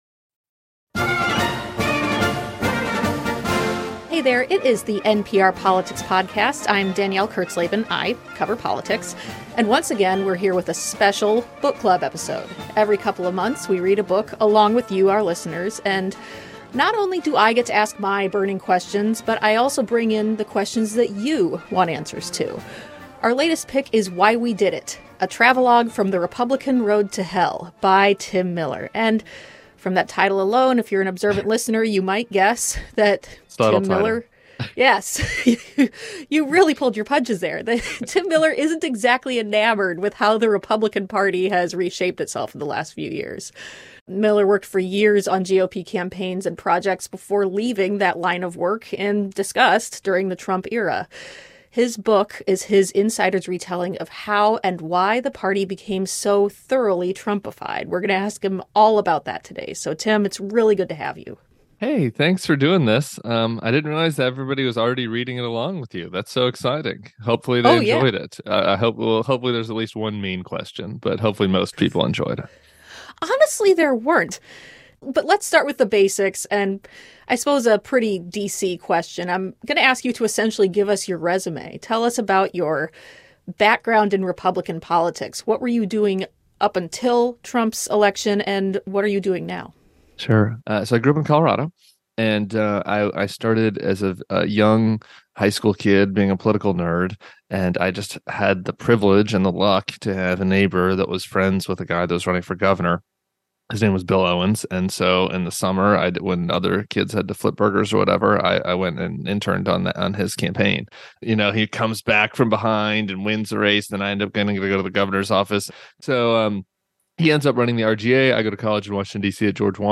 book club chat